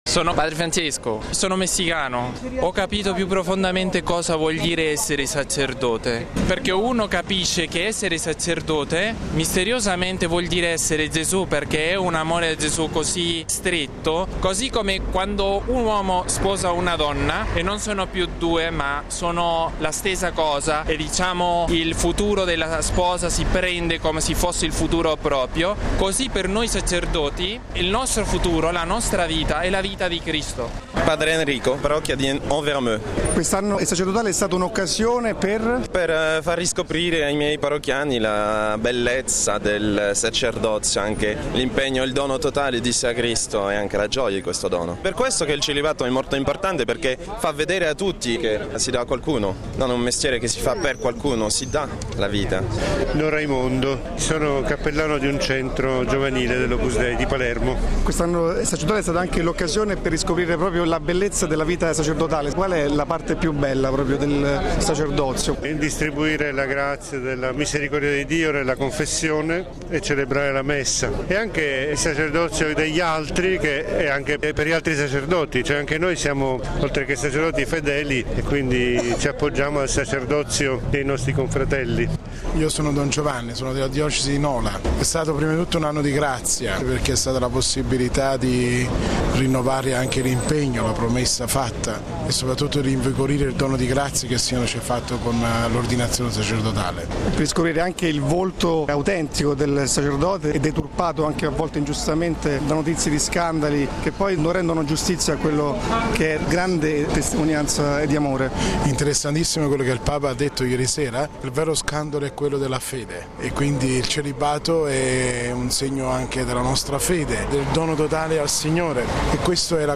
Le testimonianze di sacerdoti e laici presenti in Piazza San Pietro
◊    Fedeli, pellegrini, laici, seminaristi e soprattutto sacerdoti hanno gremito stamani Piazza San Pietro in occasione della Liturgia Eucaristica, presieduta da Benedetto XVI a chiusura dell’Anno Sacerdotale.